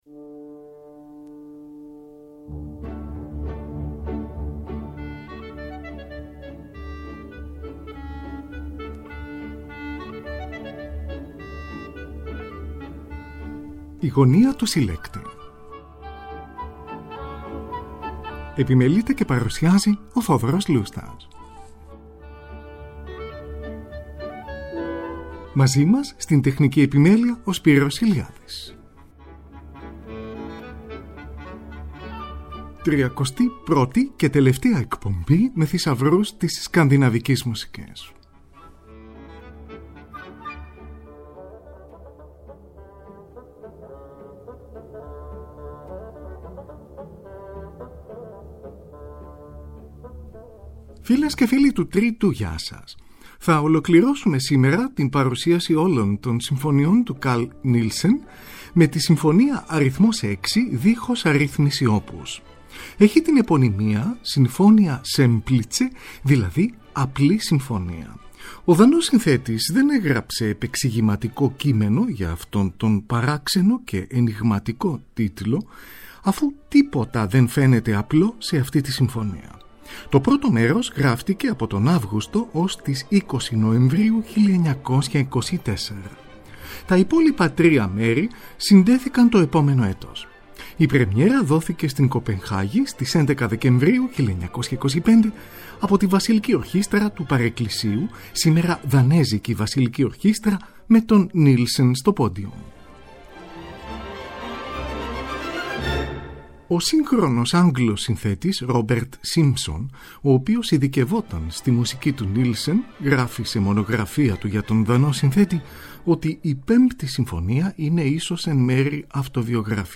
για ορχήστρα εγχόρδων.